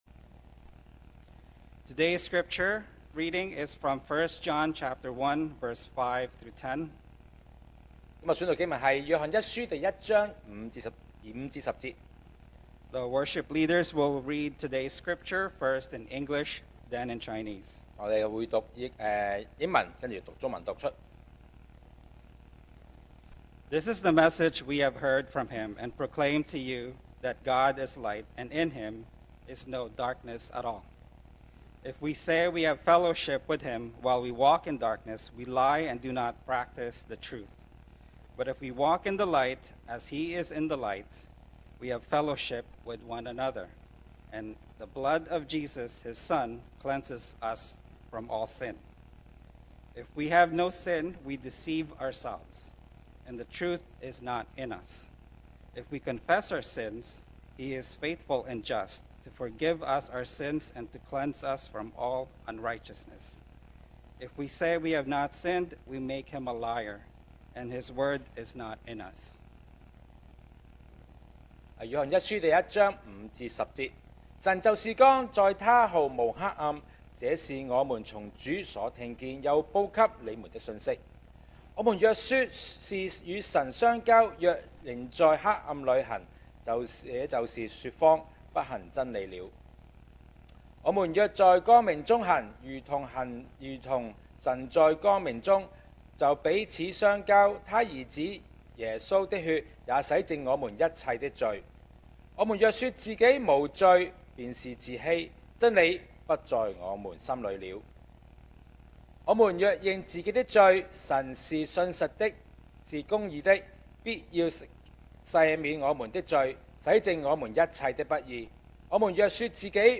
2025 sermon audios 2025年講道重溫 Passage: 1 John 1:5-10 Service Type: Sunday Morning If this true